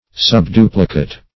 subduplicate - definition of subduplicate - synonyms, pronunciation, spelling from Free Dictionary
Search Result for " subduplicate" : The Collaborative International Dictionary of English v.0.48: Subduplicate \Sub*du"pli*cate\, a. (Math.) Expressed by the square root; -- said of ratios.